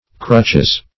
Crutch \Crutch\ (kr[u^]ch; 224), n.; pl. Crutches (-[e^]z).
crutches.mp3